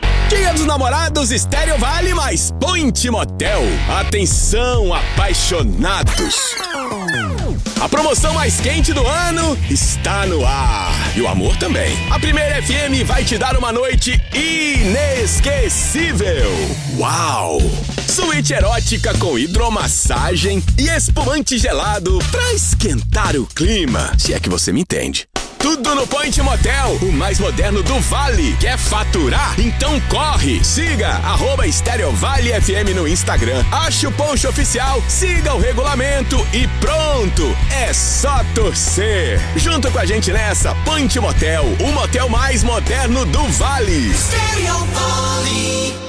Portuguese (Brazilian)
Radio Imaging
Microphone: AKG C414 XLII
Yamaha MG102c mixing console